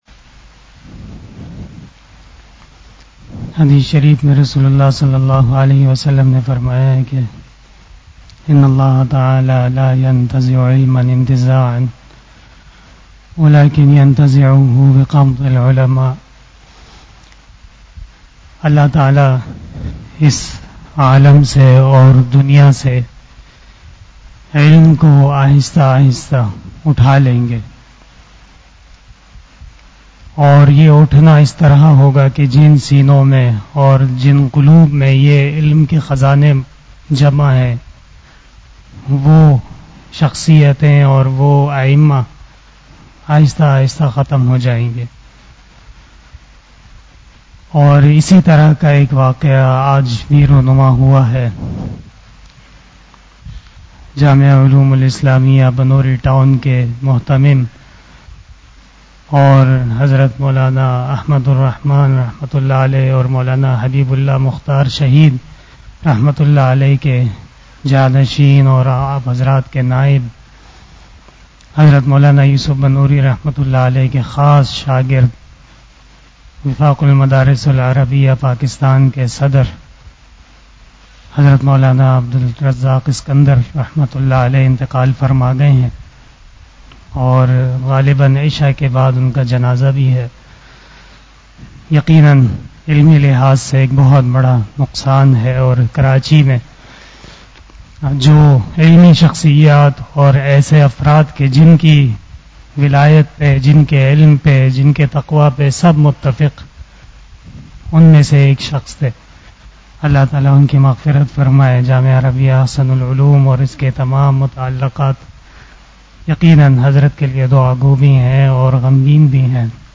After Asar Namaz Bayan
بیان بعد نماز عصر